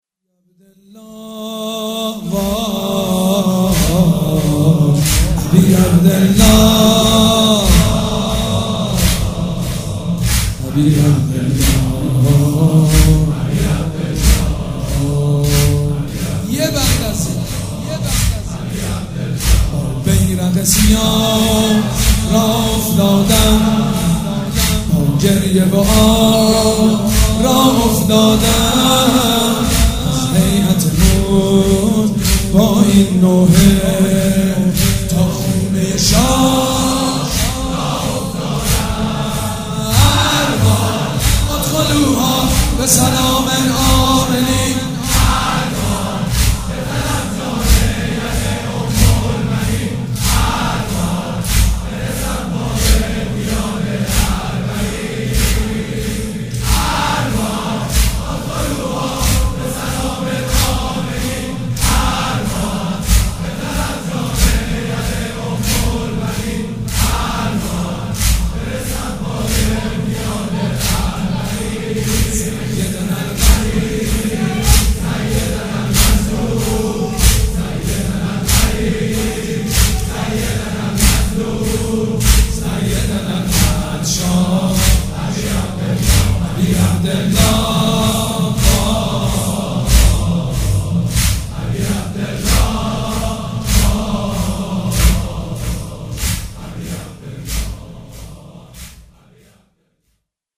شب یازدهم محرم الحرام‌
مداح
حاج سید مجید بنی فاطمه
مراسم عزاداری شب شام غریبان